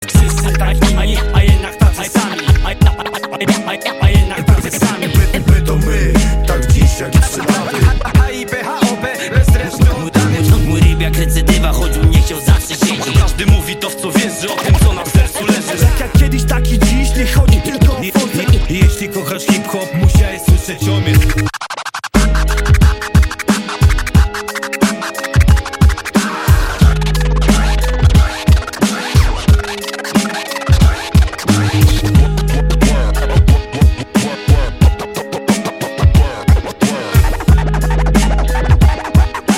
Kategoria Rap/Hip Hop